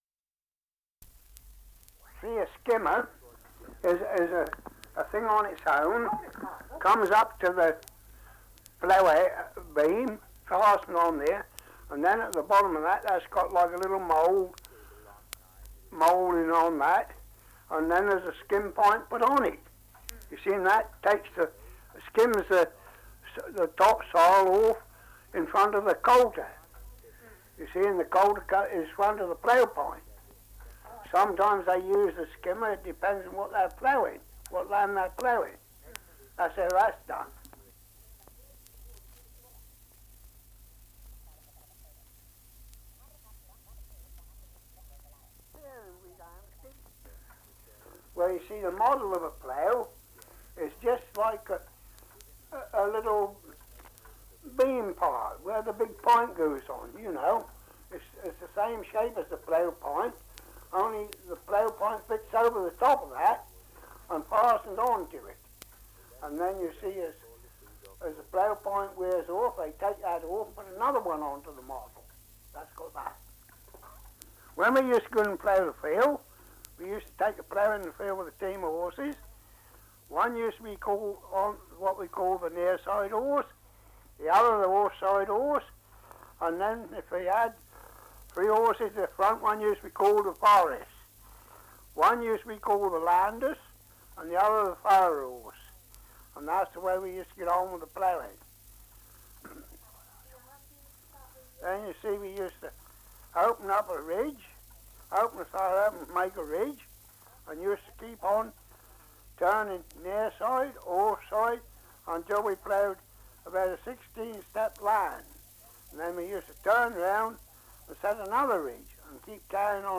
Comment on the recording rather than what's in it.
Survey of English Dialects recording in Wheathampstead, Hertfordshire 78 r.p.m., cellulose nitrate on aluminium